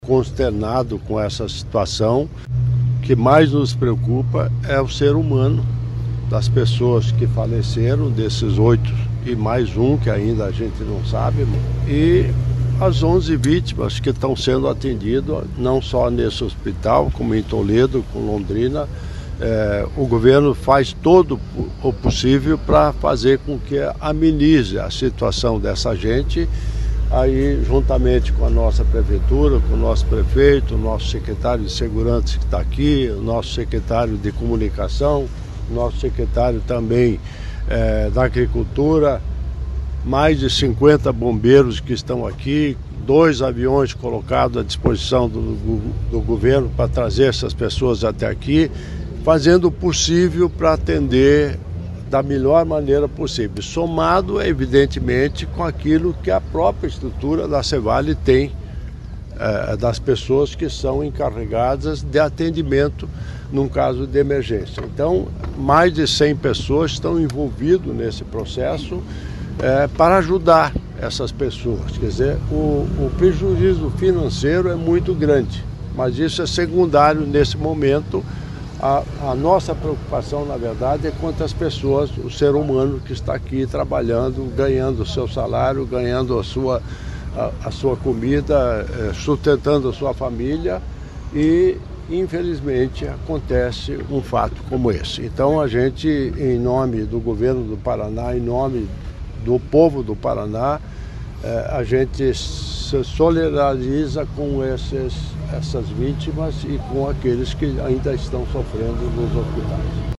Player Ouça governador em exercício Darci Piana